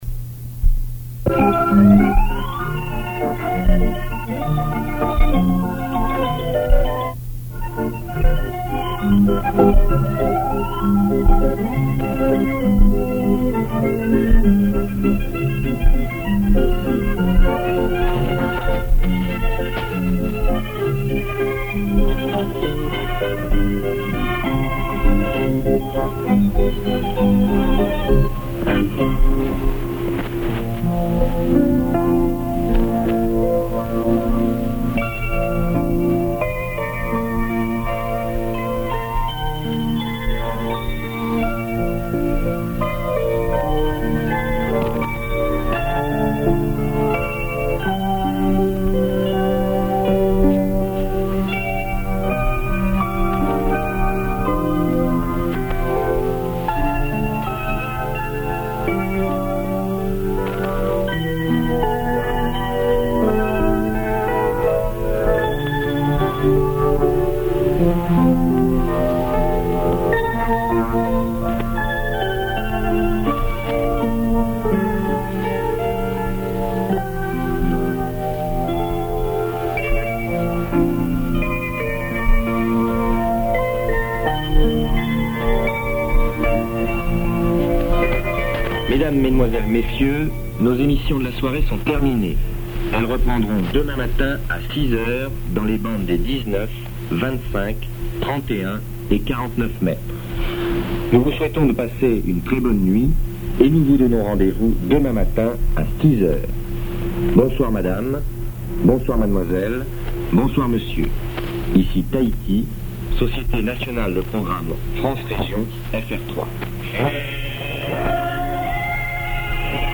Shortwave Audio Archives Shortwave Recording in MP3 Format Radio Norway signoff... Radio Tahiti signoff...